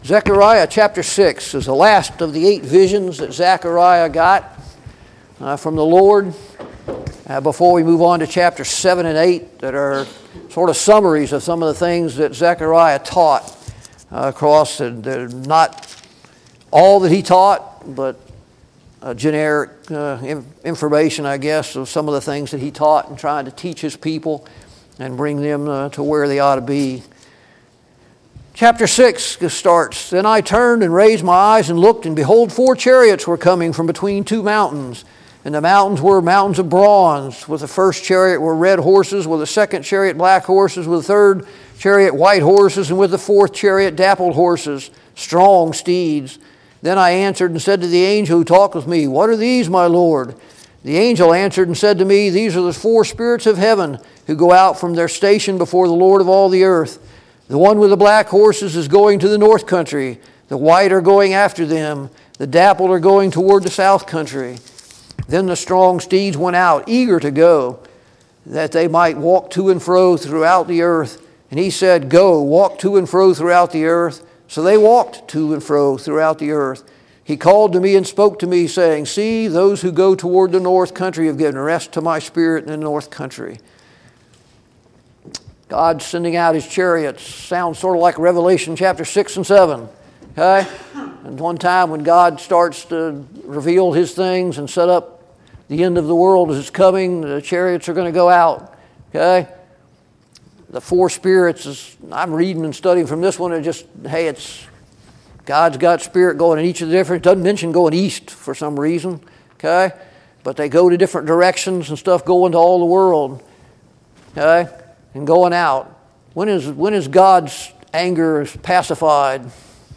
Series: Study on the Minor Prophets Service Type: Sunday Morning Bible Class « 24.